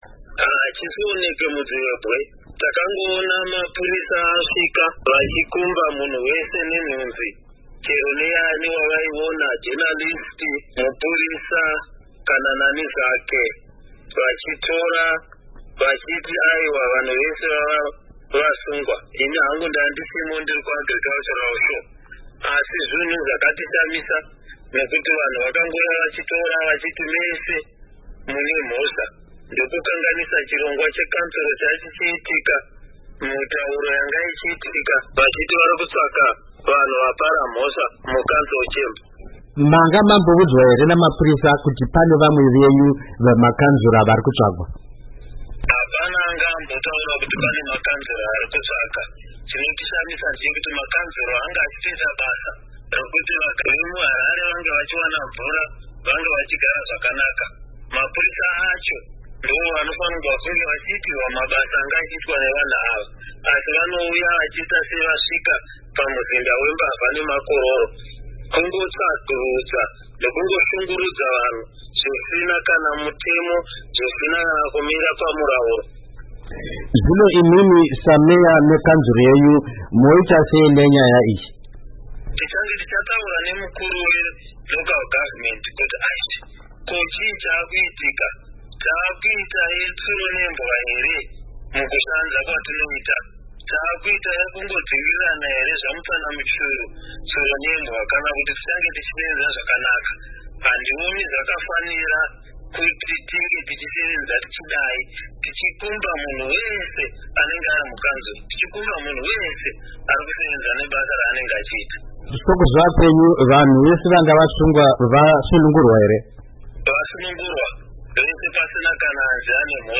Hurukuro naVaJacob Mafume